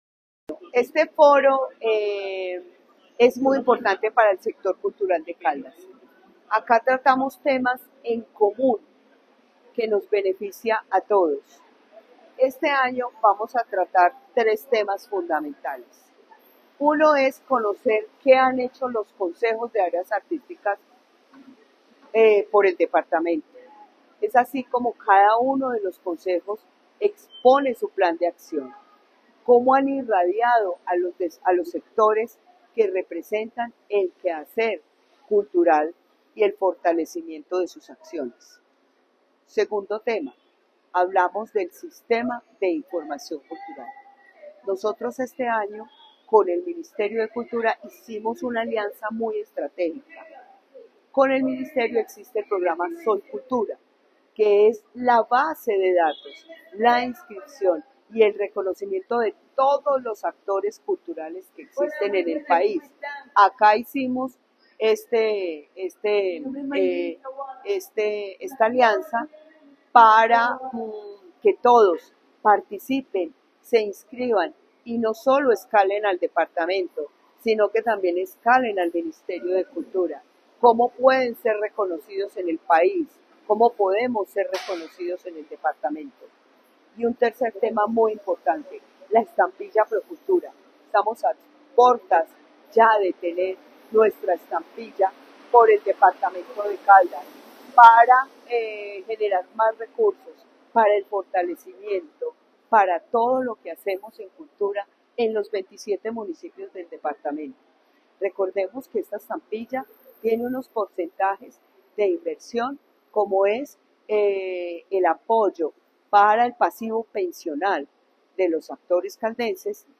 Secretaria de Cultura de Caldas, Luz Elena Castaño Rendón.